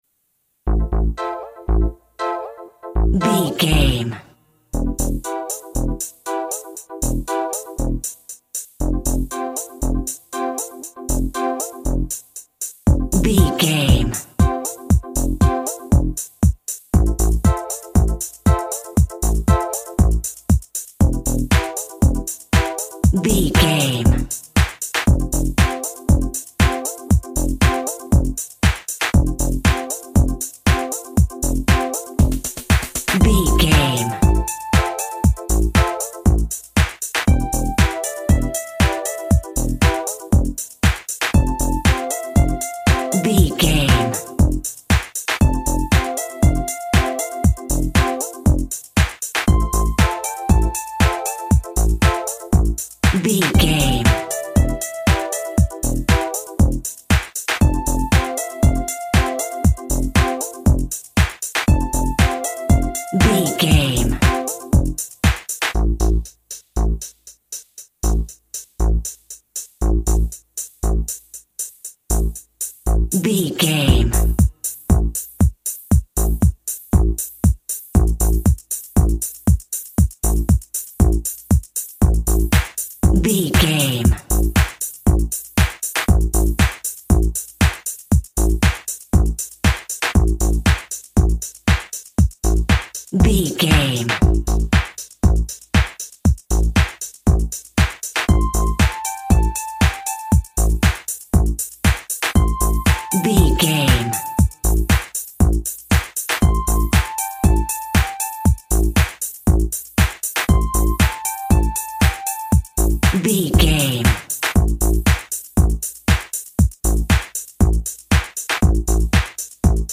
Cheesy Eighties Techno.
Ionian/Major
groovy
dreamy
smooth
futuristic
drum machine
synthesiser
house music
energetic
synth lead
synth bass